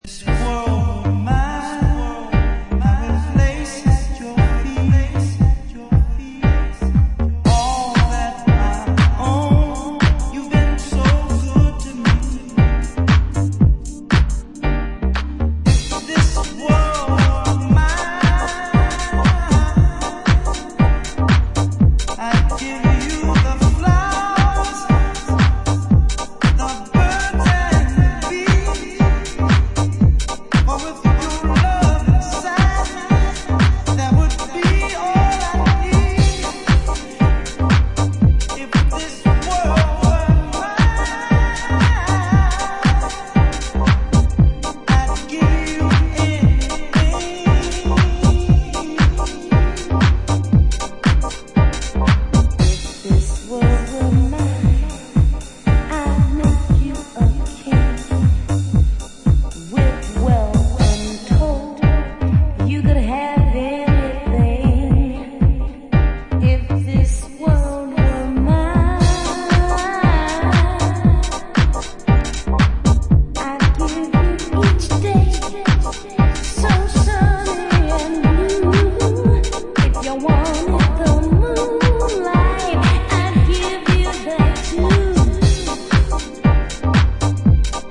House
sweet deep house work out